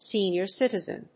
sénior cítizen